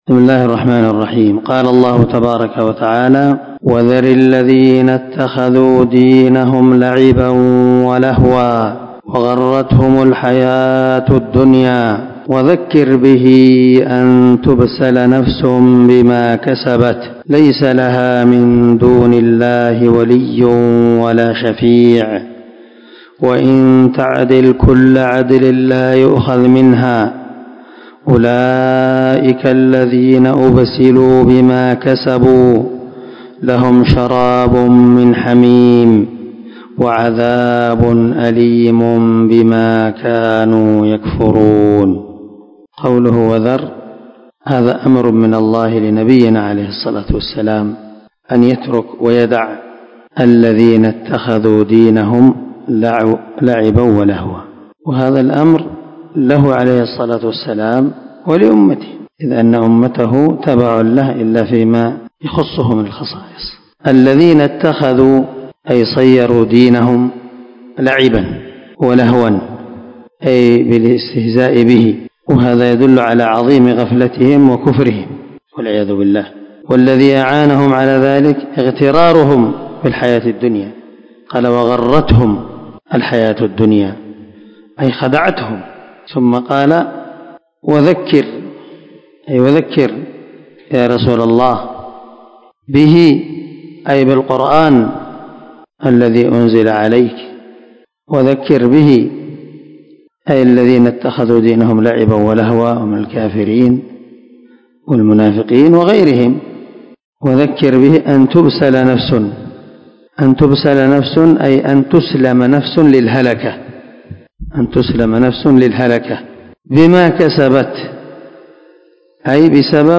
414الدرس 22 تفسير آية ( 70 ) من سورة الأنعام من تفسير القران الكريم مع قراءة لتفسير السعدي